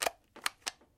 枪械/佳能 " 手枪的全面重装（混合）。
描述：这是在重装手枪时与麦克风紧密记录的。订单是Mag Out，Mag In然后枪支滑动关闭。
标签： 点击 手枪 手枪 弹匣 重新加载 重装 替换 武器 夹子 拟音 声音
声道立体声